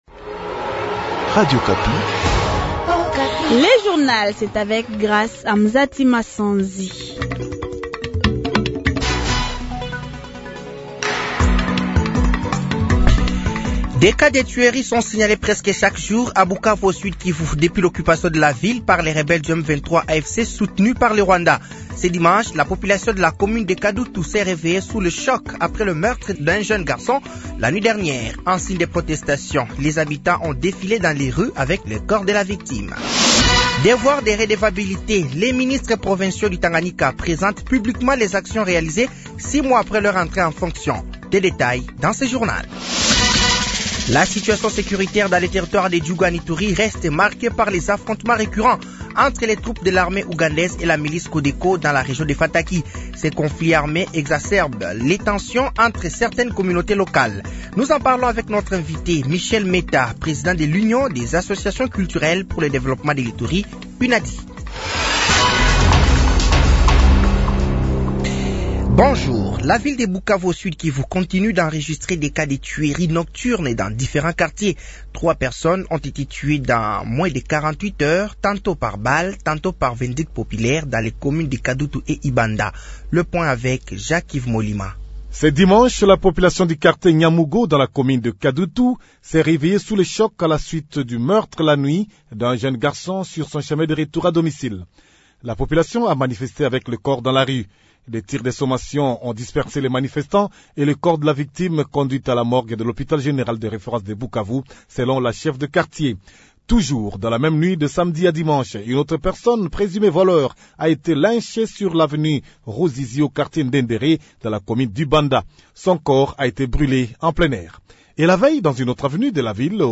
Journal français de 15h de ce dimanche 30 mars 2025